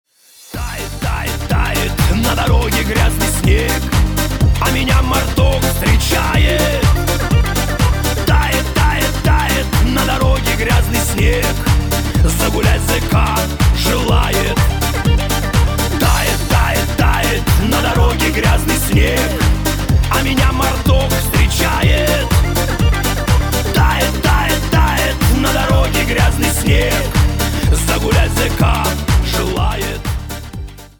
русский шансон
блатные